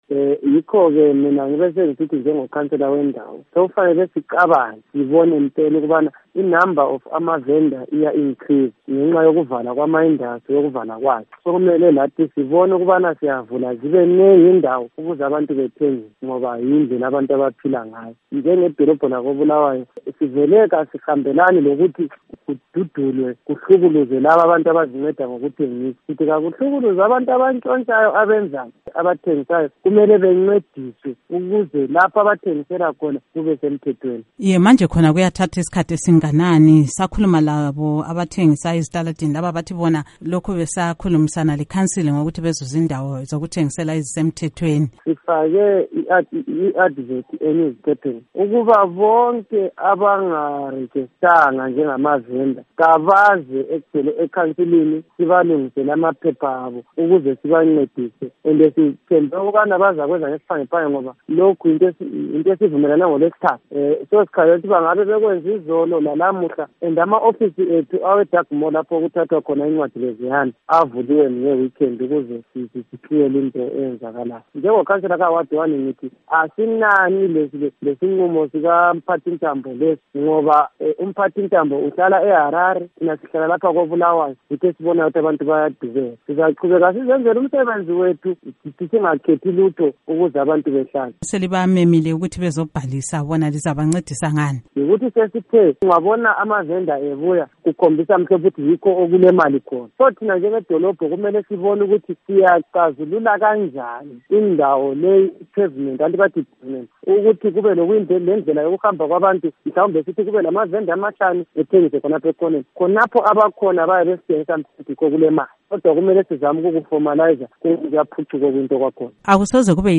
Ingxoxo LoKhansila Mlandu Ncube